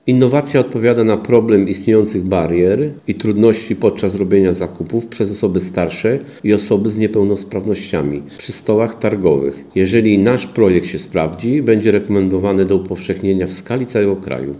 Mówi burmistrz Dębicy, Mariusz Szewczyk.